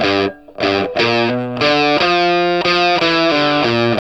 WALK1 60 G.A.wav